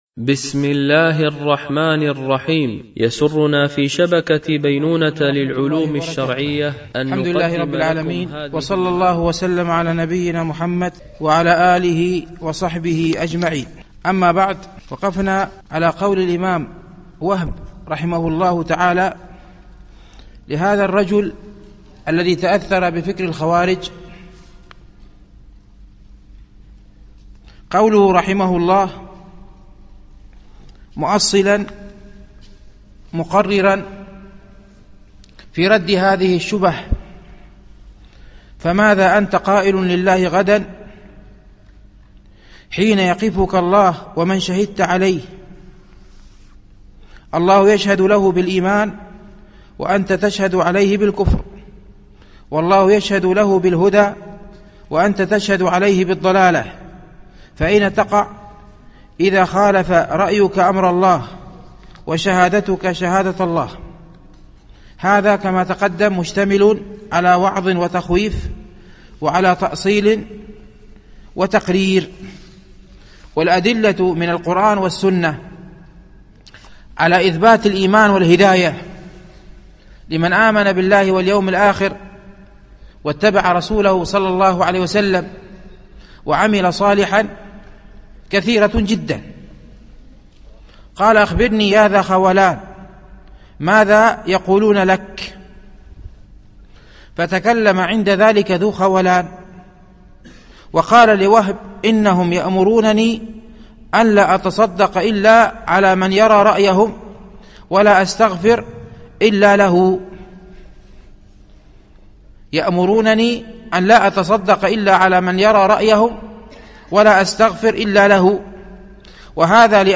شرح رسالة وهب بن منبه في نصيحته لأحد الخوارج _ الدرس الرابع